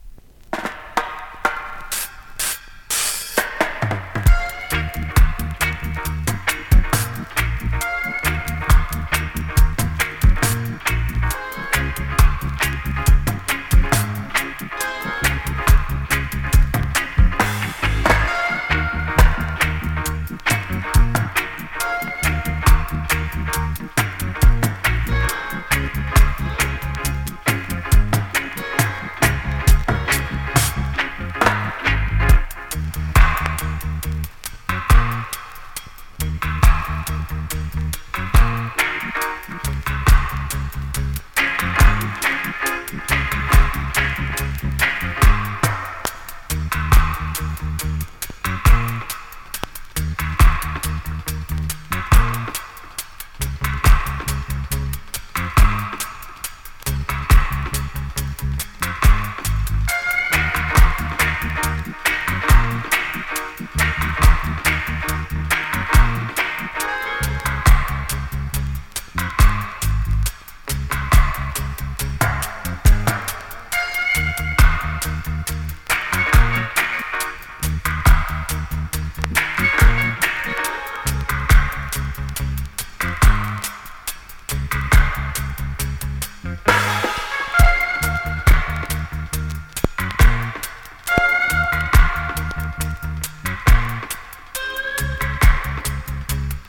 ※前半に数回目立つノイズ有
B面の試聴はこちらからどうぞ。